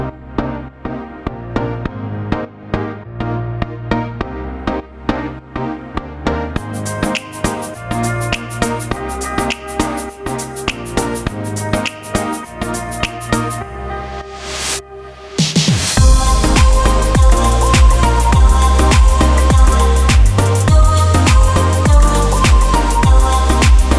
Remix - Duet Version Pop (2010s) 3:42 Buy £1.50